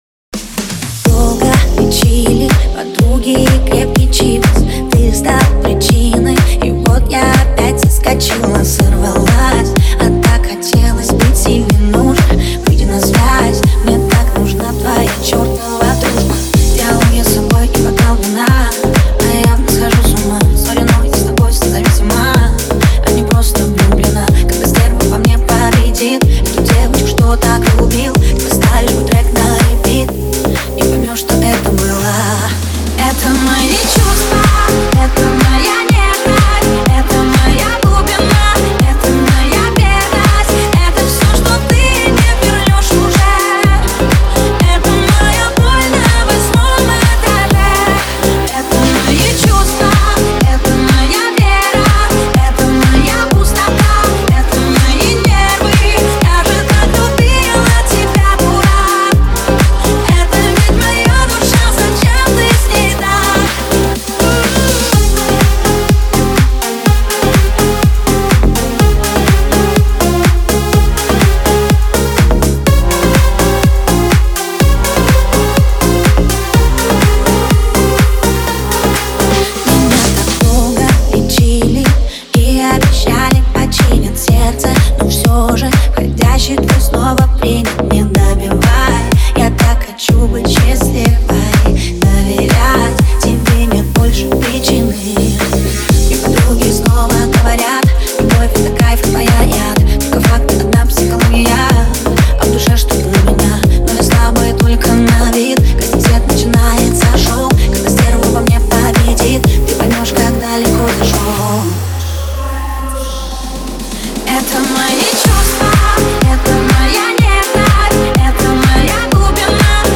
это яркая и запоминающаяся песня в жанре поп